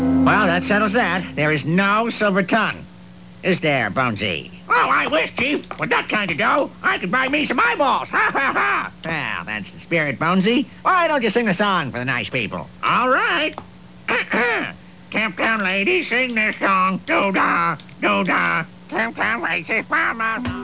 Wiggum pretending to make a skull talk